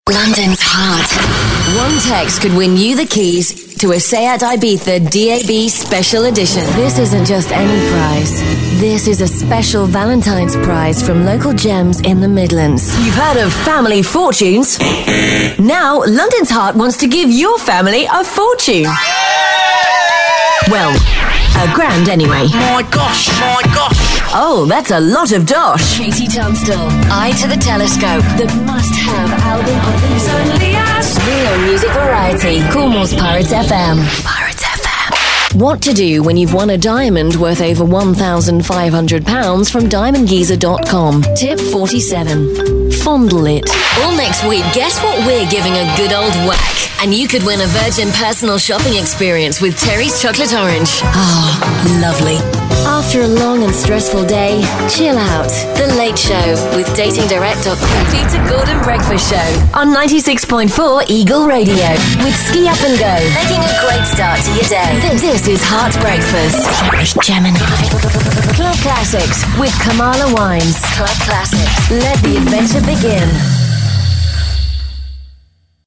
Voice Samples: Promos & Imaging Demo
EN UK
female